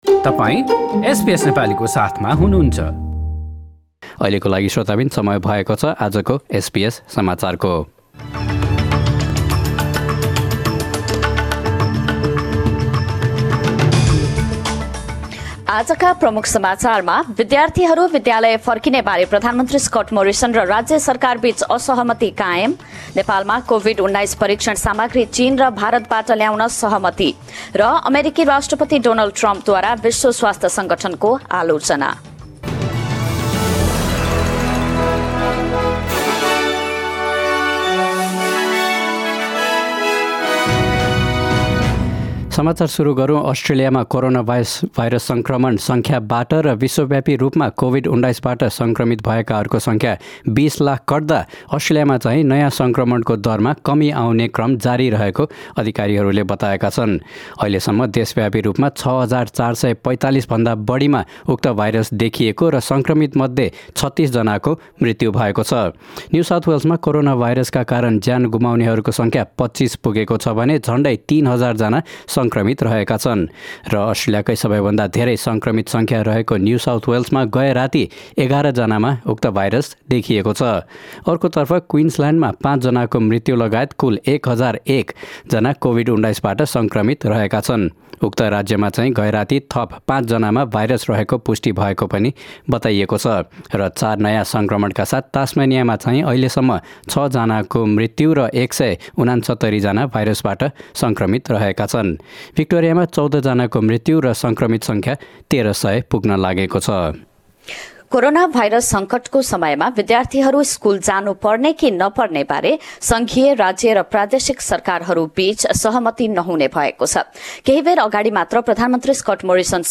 Listen to the latest news headlines from Australia in the Nepali language.